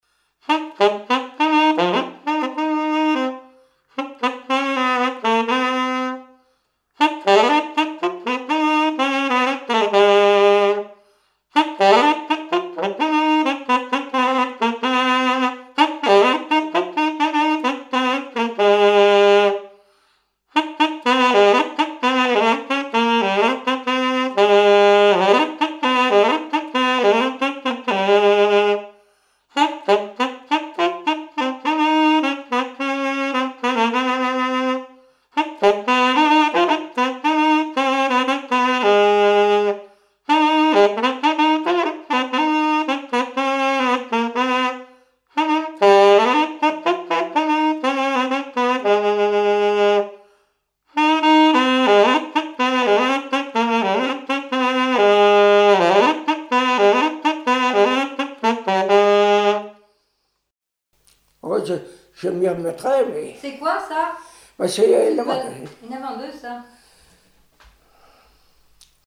danse : quadrille
Musique et témoignages
Pièce musicale inédite